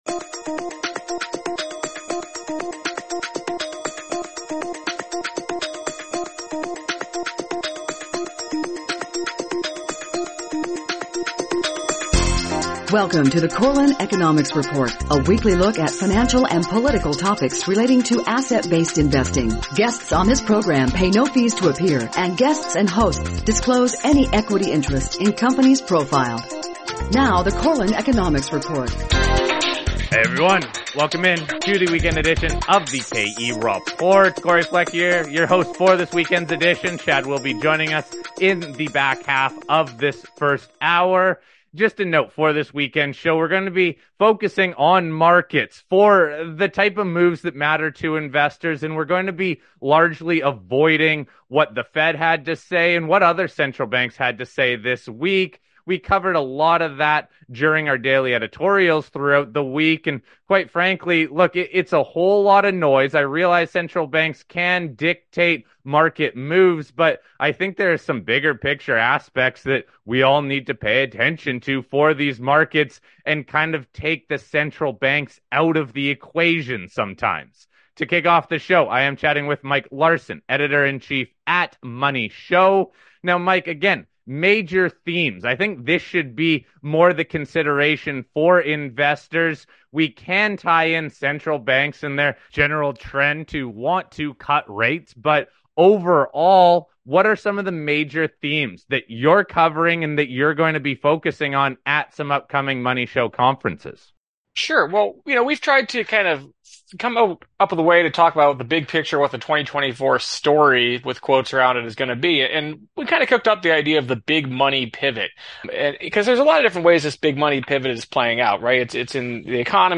On this Weekend’s Show we again feature two generalist investors to share where they are making money in the markets. Diversification is a major theme right now as a number of lagging sectors are playing catch up to the Magnificent 7.